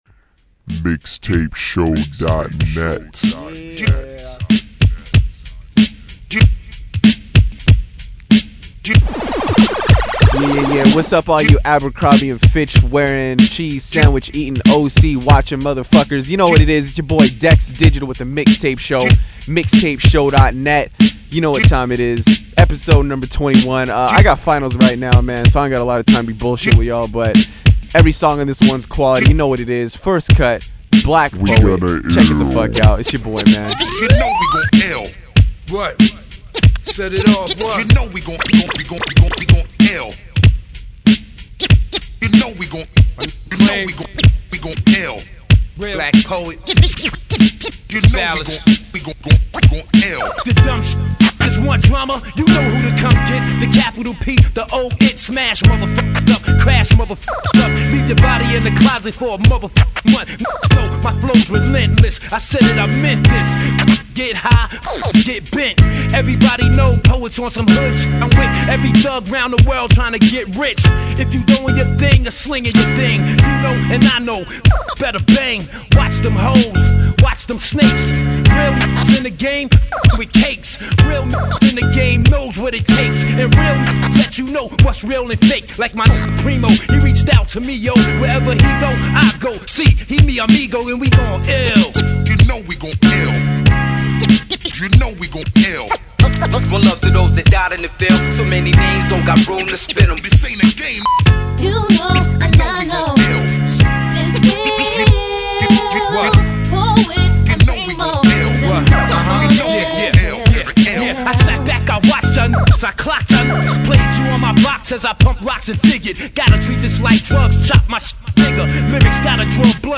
This is a good little mixtape